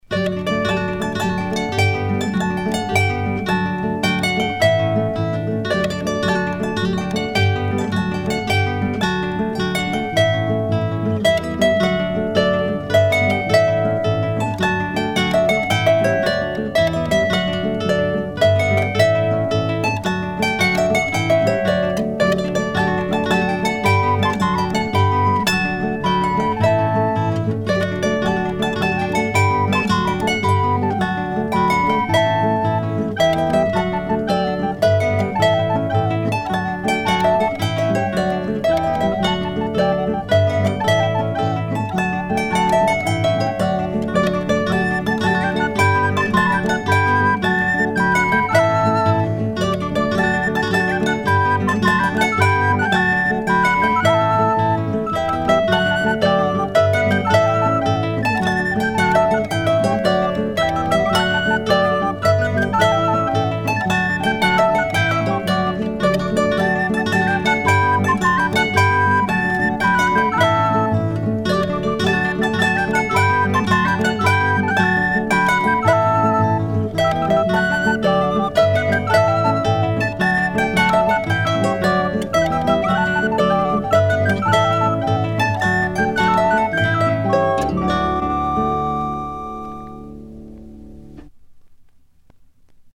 Genre strophique
Chants et musiques traditionnels des milieux maritimes